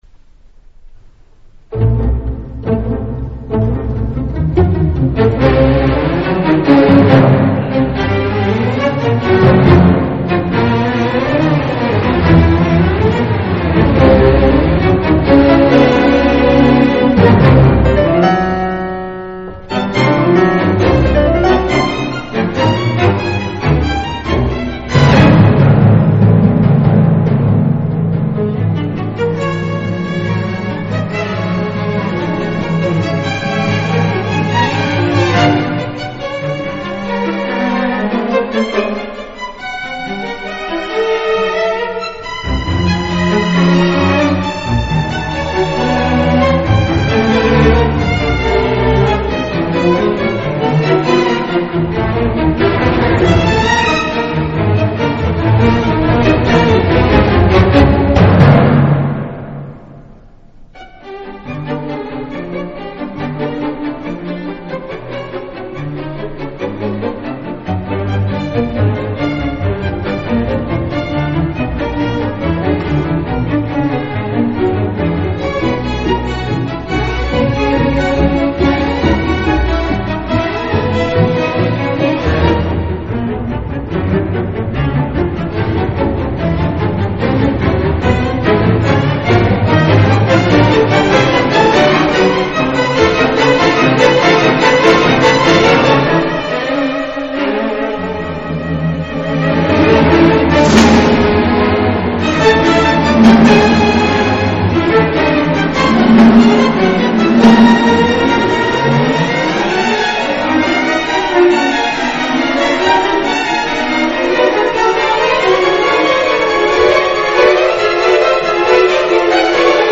(指揮)パーヴォ・ヤルヴィ
(管弦楽)ＮＨＫ交響楽団　通して聽く